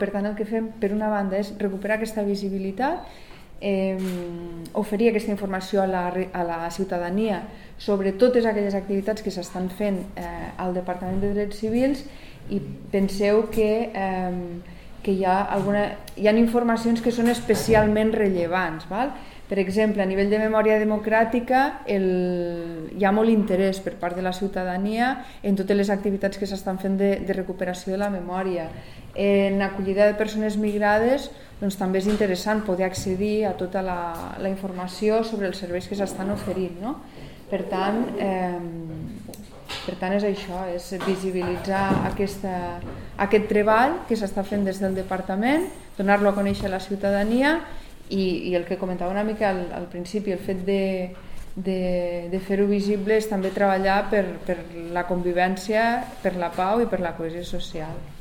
tall-de-veu-de-la-tinent-dalcalde-sandra-castro-sobre-el-nou-web-de-drets-civils-i-memoria-democratica